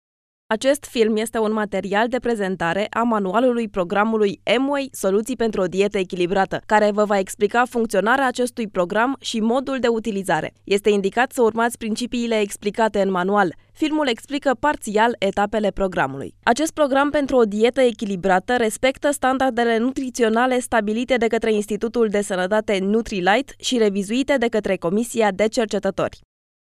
Sprecherin rumänisch für Werbung, TV, Industrie, Radio etc.
Sprechprobe: Industrie (Muttersprache):
Professional female voice over talent from Romania